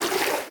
swim3.ogg